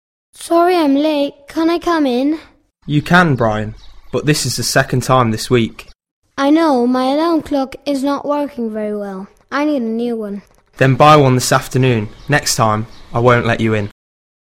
Descripci�n: El video representa la conversaci�n entre varias personas (protagonistas que aparecen y texto asociado se muestra m�s abajo).